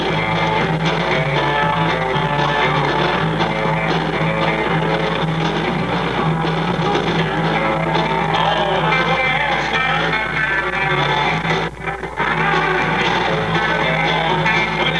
Joe Don's Guitar Solo (2)
joedonrooney-guitarsolo1.wav